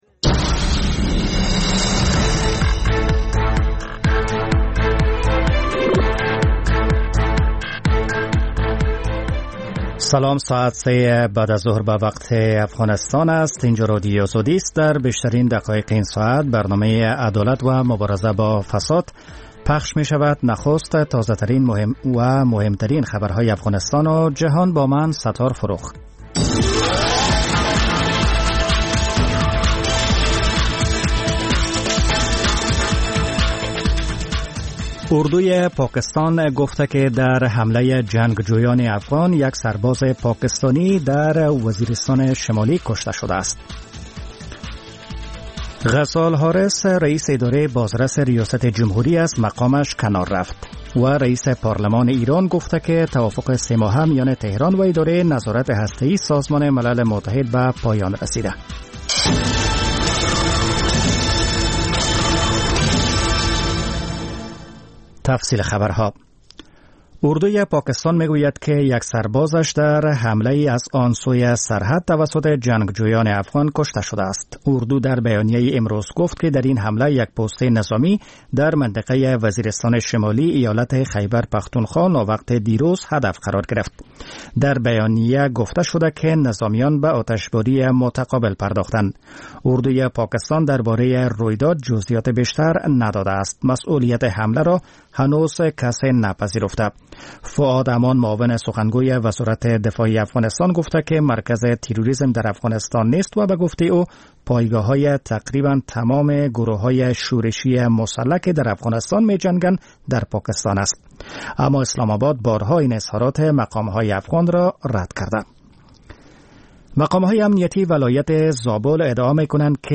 خبر ها